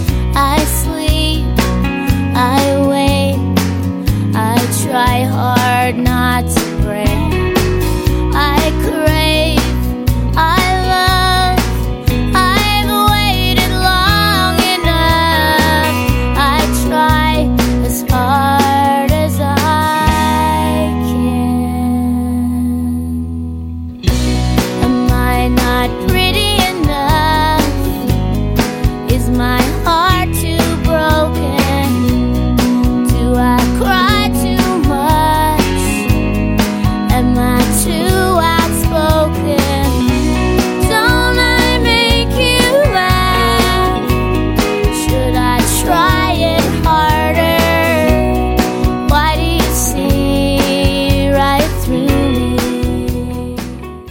Country & Western Hits